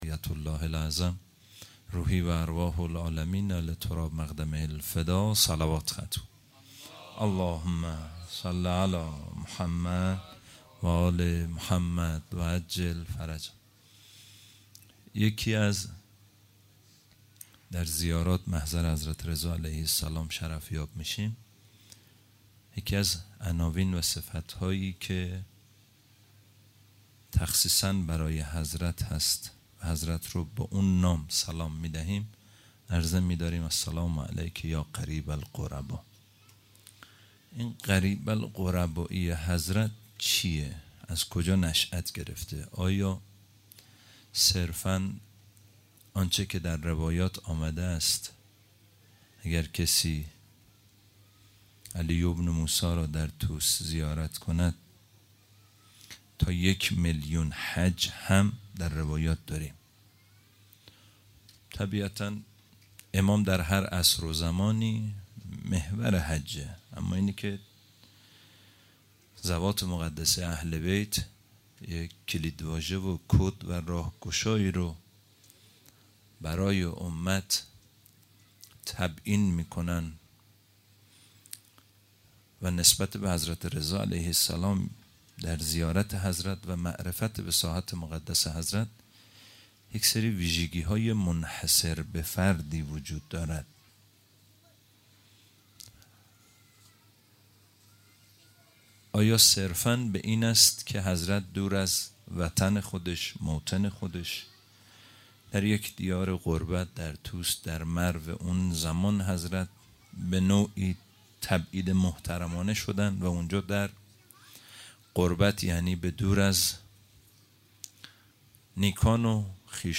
سخنرانی جلسه هفتگی 2مرداد ماه